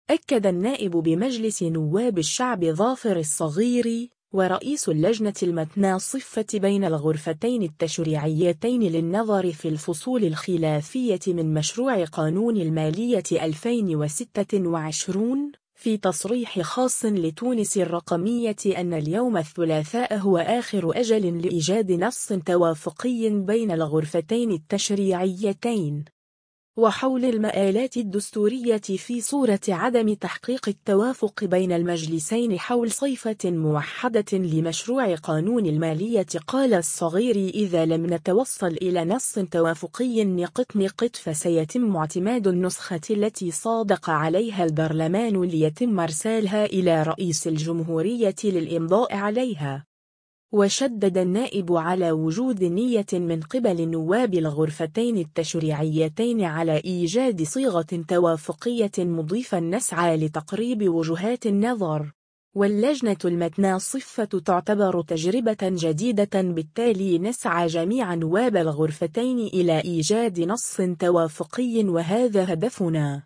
أكد النائب بمجلس نواب الشعب ظافر الصغيري،ورئيس اللجنة المتناصفة بين الغرفتين التشريعيتين للنظر في الفصول الخلافية من مشروع قانون المالية 2026، في تصريح خاص لـ”تونس الرقمية” أن اليوم الثلاثاء هو آخر أجل لإيجاد نص توافقي بين الغرفتين التشريعيتين.